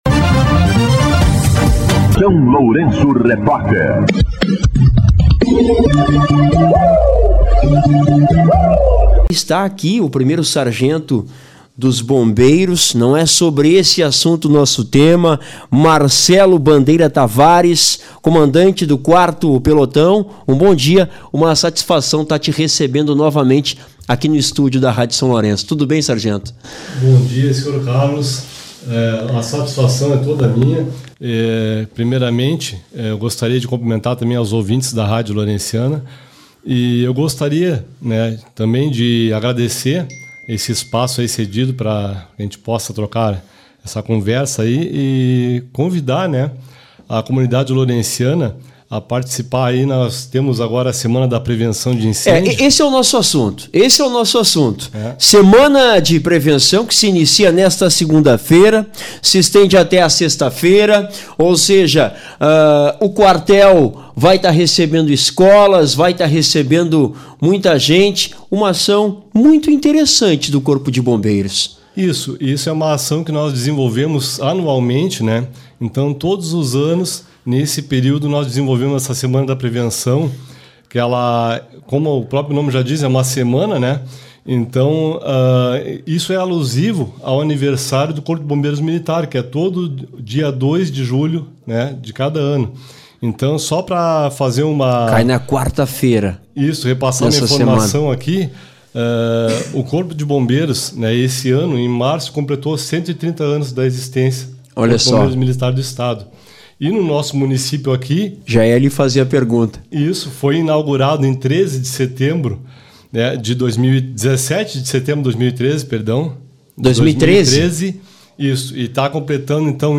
concedeu entrevista ao SLR RÁDIO na manhã desta segunda-feira (30), para abordar o início da Semana da Prevenção (de 30/06 a 04/07), onde a Corporação realiza atividades com a comunidade, como visitação nas escolas, visitação das escolas no Quartel, e demais atividades.